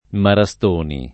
[ mara S t 1 ni ]